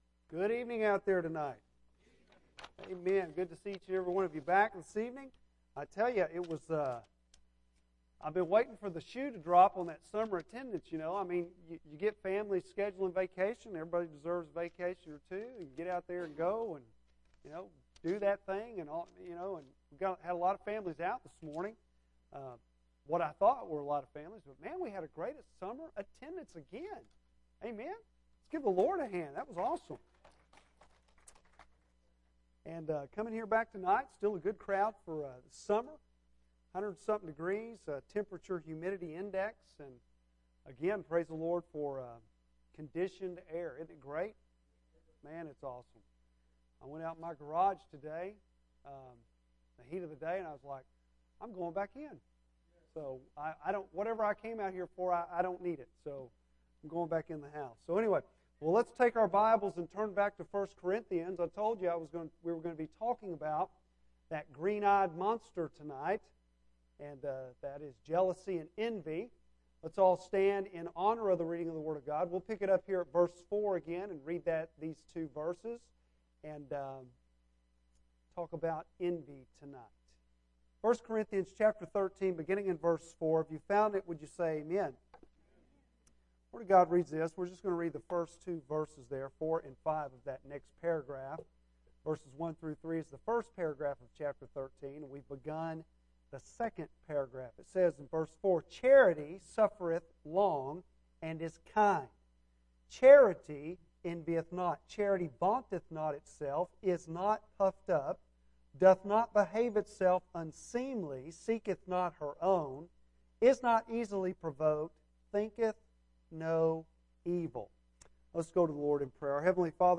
Bible Text: I Corinthians 13:4-5 | Preacher